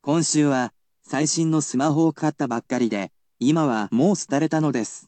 I will also tell you the pronunciation of the word using the latest in technological advancements.